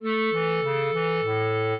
clarinet
minuet7-5.wav